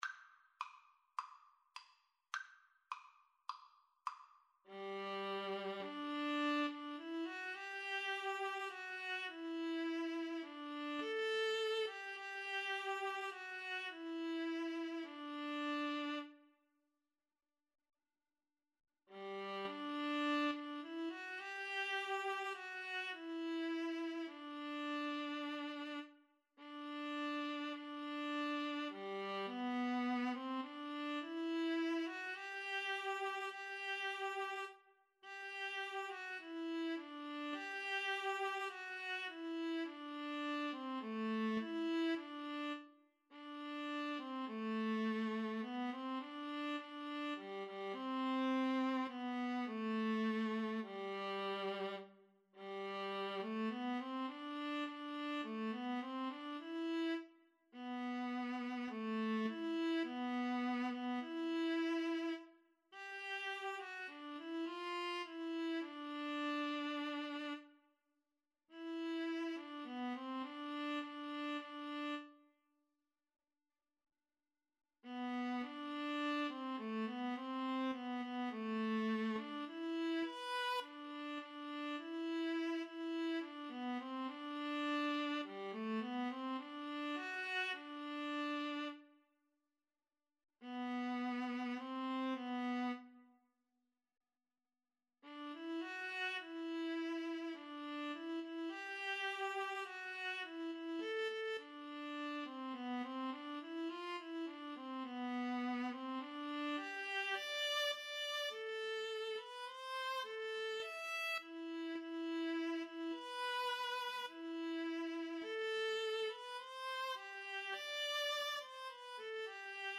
Allegro ( = 104-120) (View more music marked Allegro)
Classical (View more Classical Violin-Viola Duet Music)